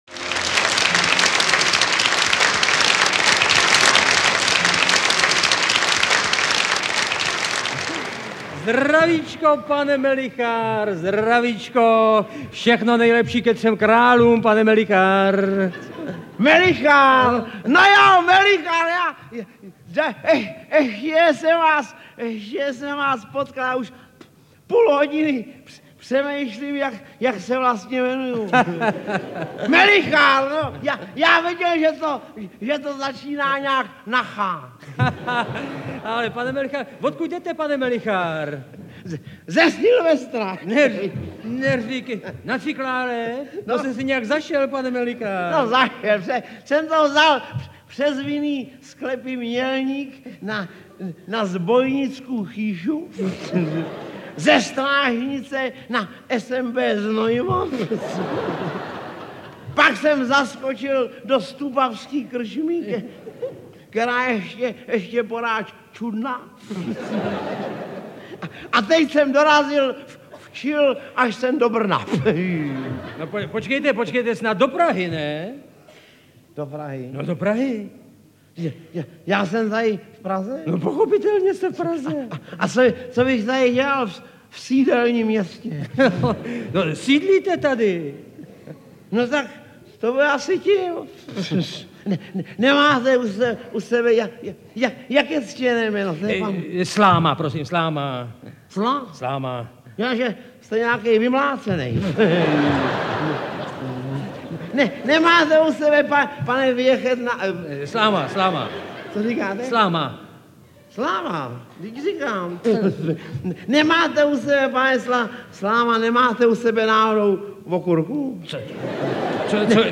Interpret:  Jaroslav Štercl
LP Večer s Jaroslavem Šterclem, vydal Supraphon v roce 1977, V jeho digitální podobě se můžete nyní znovu setkat s tímto legendárním komikem, s jeho slovním mistrovstvím a uměním gradování point.